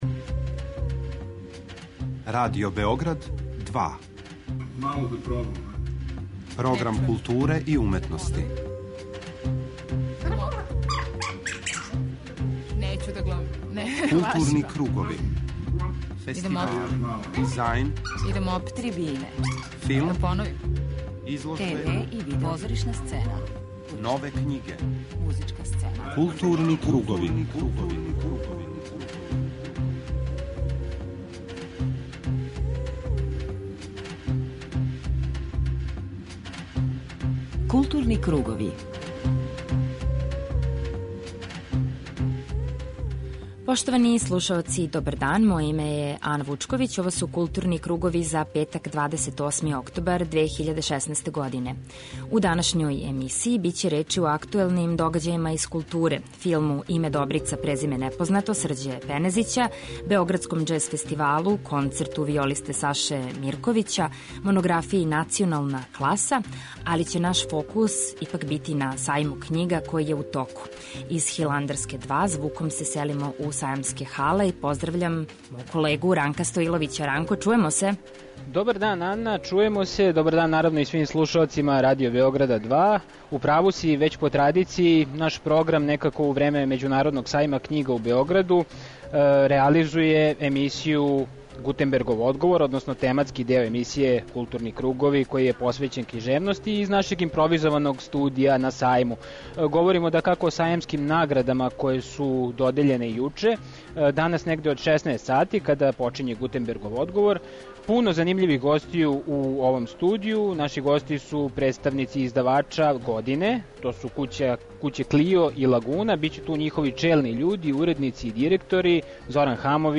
У првом сату 'Културних кругова' говоримо о актуелним догађајима из културе, док 'Гутенбергов одговор', тематски блок посвећен књижевности, реализујемо уживо са Сајма књига у Београду.